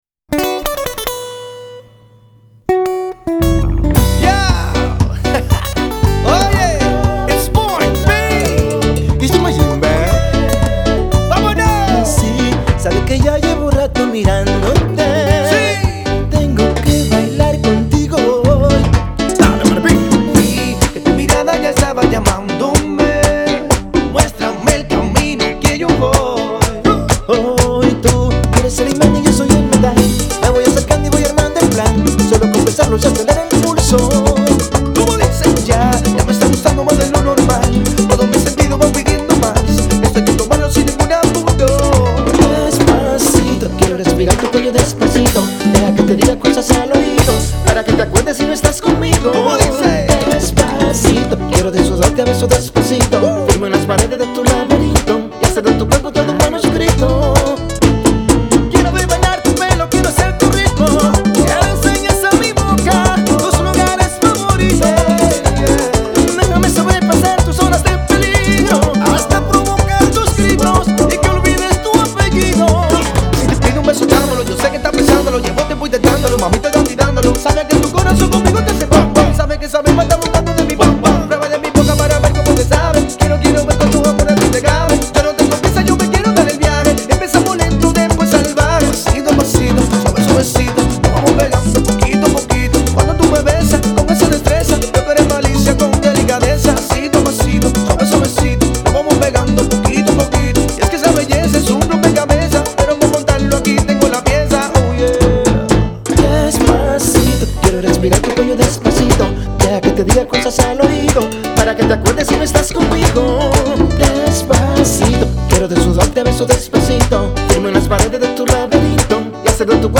urban merengue